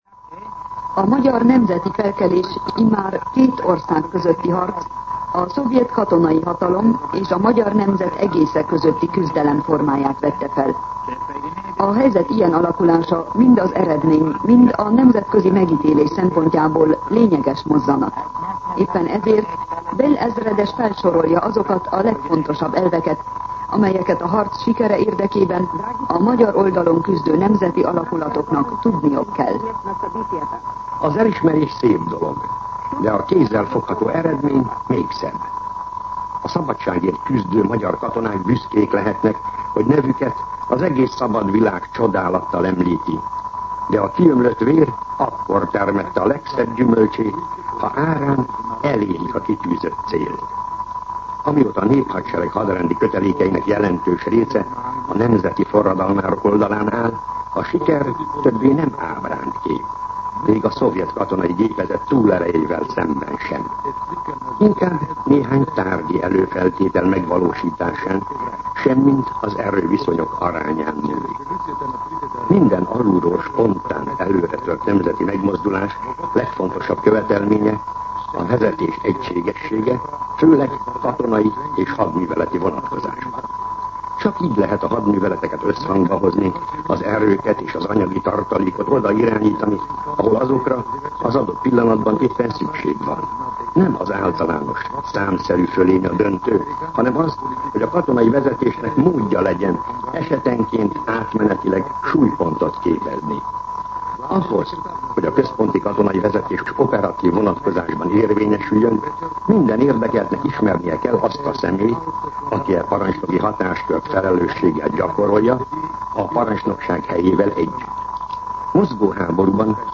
Katonapolitikai kommentár